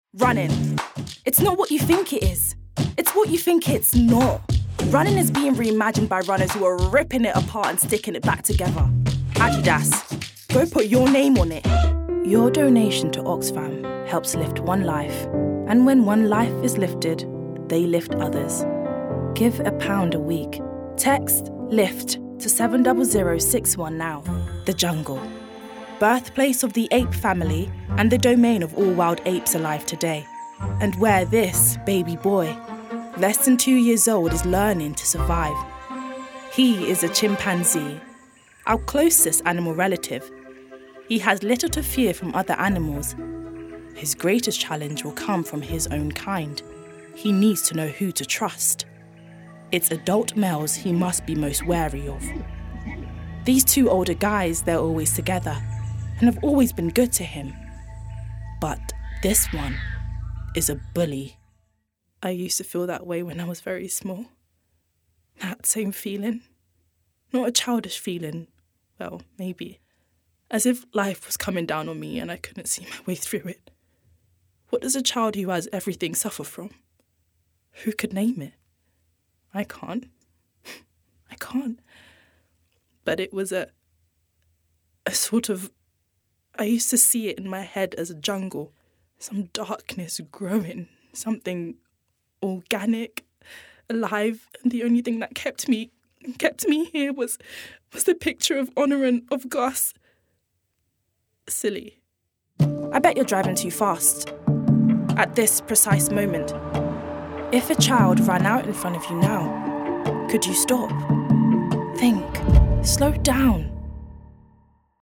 Voicereel:
VOCAL VARIATIONS
BRITISH ISLES: Heightened RP, Contemporary RP
GLOBAL: African American, South African, Nigerian
Alto